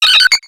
Cri de Togetic dans Pokémon X et Y.